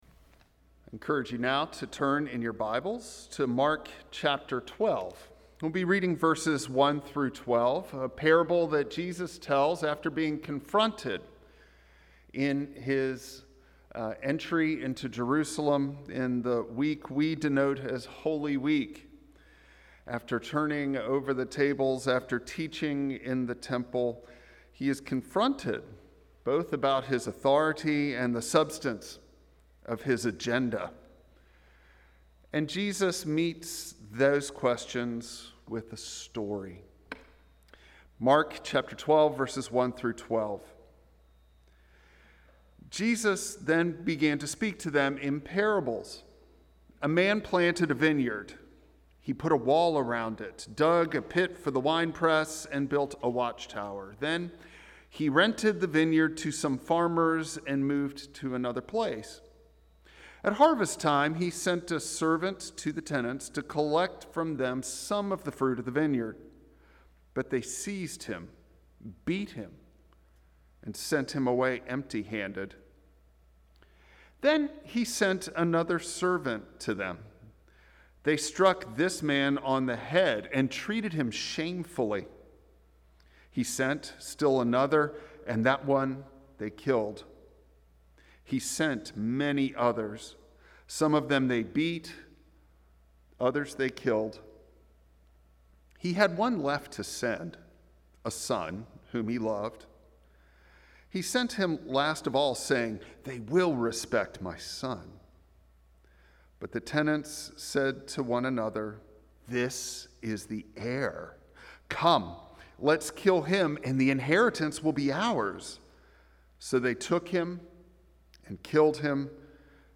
This updated version corrects the audio hiccups.
Passage: Mark 12:1-12 Service Type: Traditional Service Bible Text